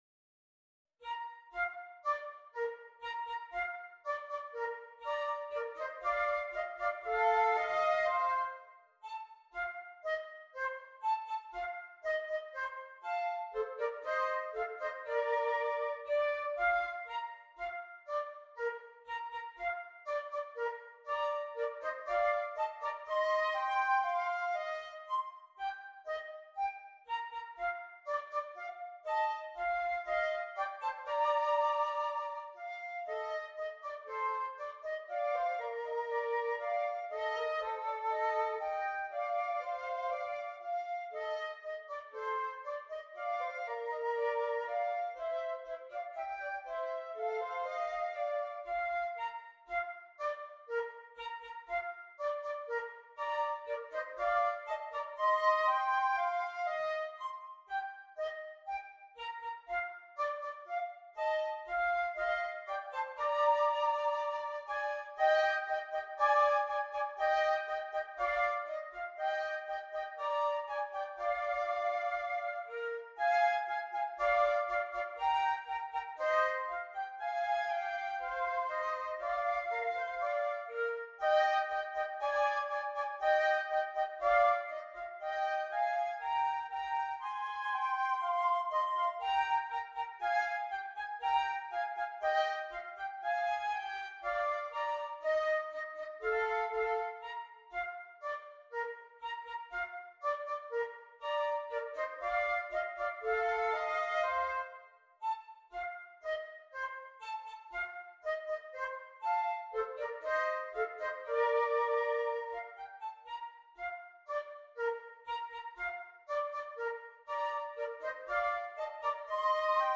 2 Flutes